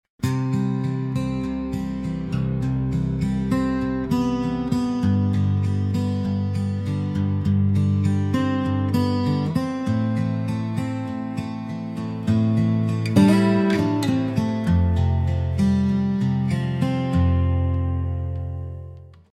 This track represents the C Ionian Mode.